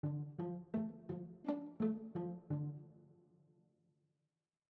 RythmGame/SimpleGame/media/chords/variation2/D.mp3 at 382aff73cac5151bc645201c63c13107cad5c7fd